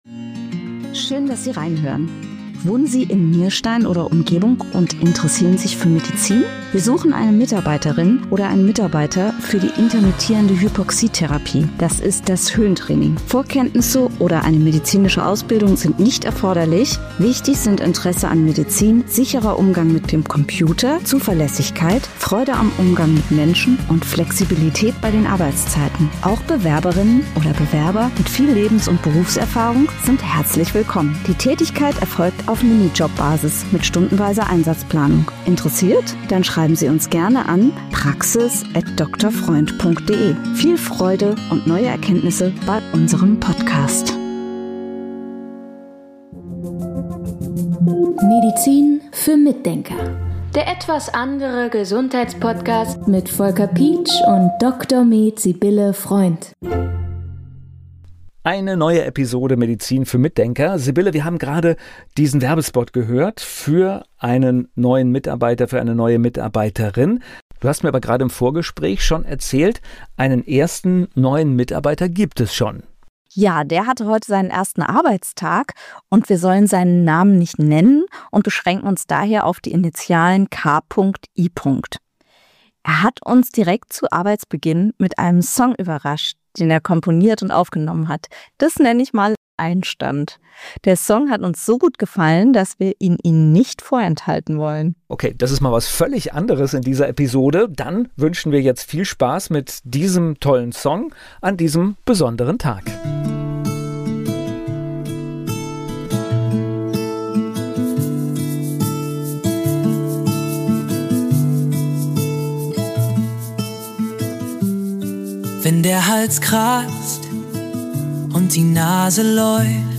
Musik!